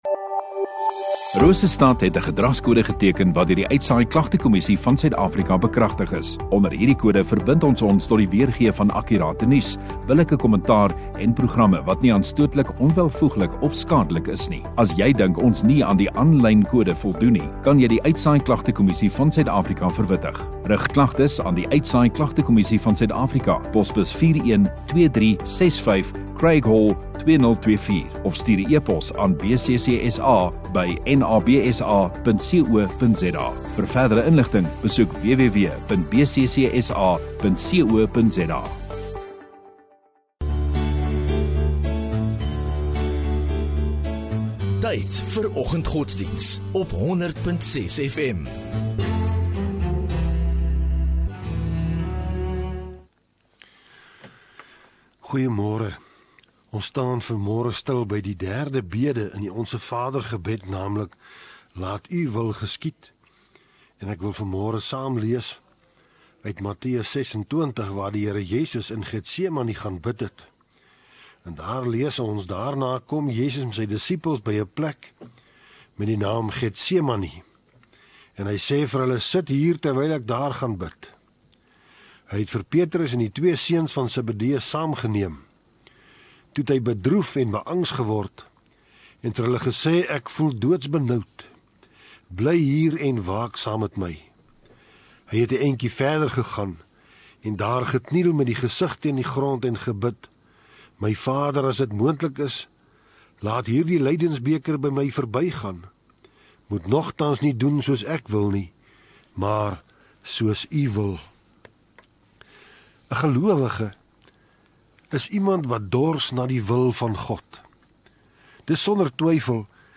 25 Sep Donderdag Oggenddiens